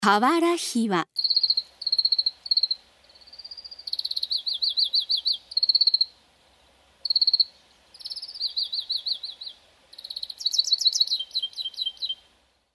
カワラヒワ
【鳴き声】普段は「キリキリコロコロ」「キリリリリ」などと鳴き、繁殖期は「キリキリコロコロビィーン」とさえずる。
カワラヒワの鳴き声（音楽：209KB）
kawarahiwa.mp3